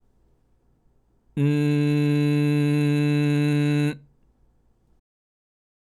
まずは口を閉じた状態で、普段喋っているような「ん」の声を出してみて下さい。
※喉頭は通常位置のグーの声(ん)